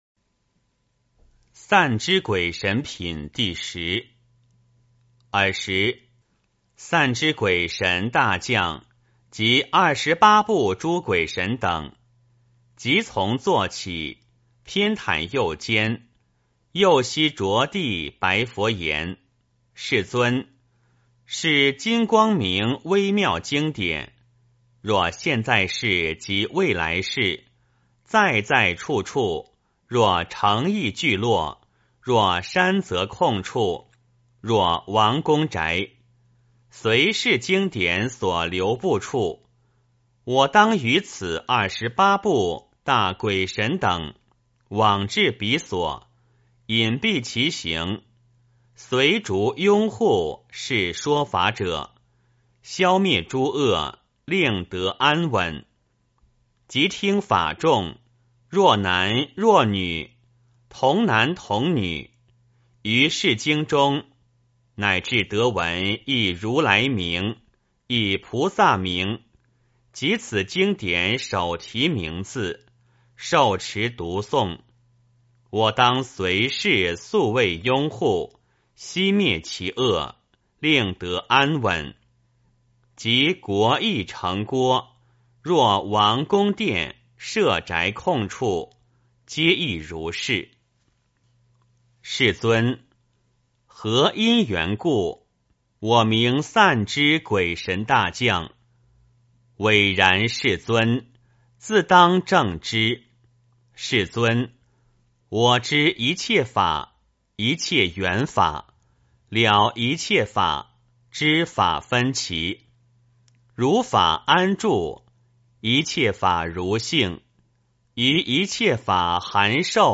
金光明经.散脂鬼神品第十 - 诵经 - 云佛论坛